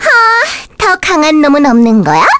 Viska-Vox_Victory_kr.wav